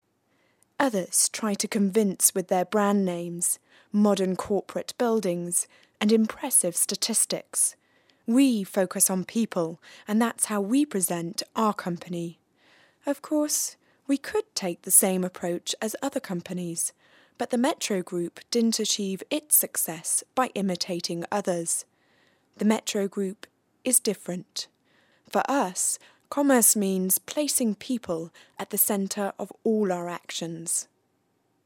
dynamisch, frisch, mittelkräftig, sanft, seriös, weich
britisch
Sprechprobe: Werbung (Muttersprache):
dynamic, fresh, soft, serious voice.